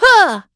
Valance-Vox_Attack1.wav